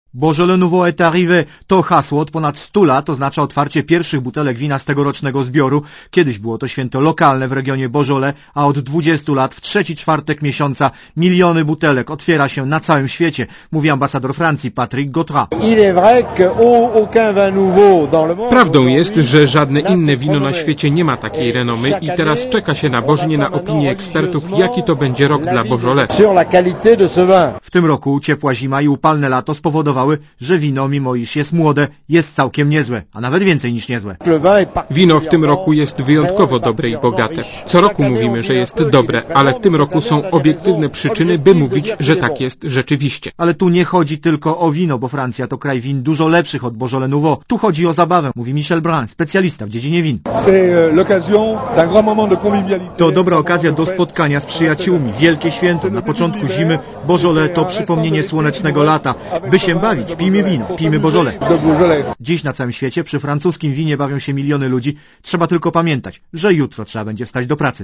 Źródło zdjęć: © PAP 20.11.2003 16:19 ZAPISZ UDOSTĘPNIJ SKOMENTUJ Komentarz audio (264Kb)